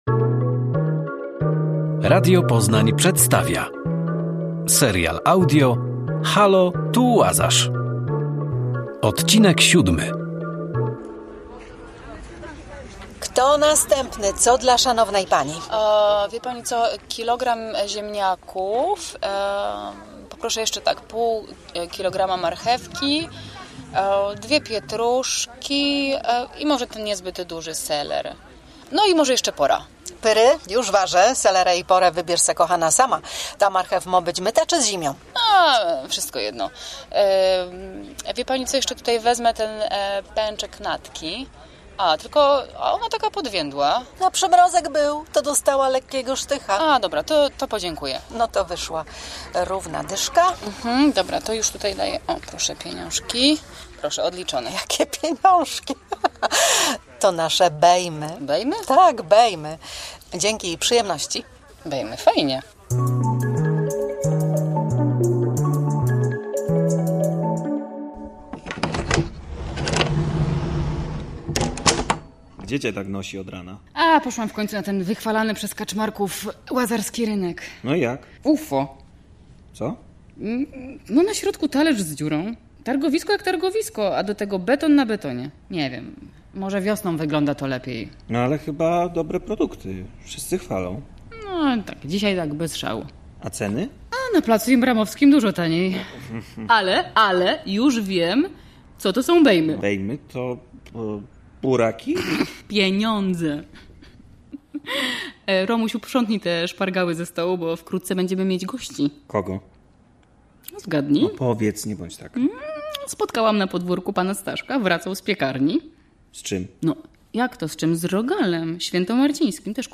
Serial audio Radia Poznań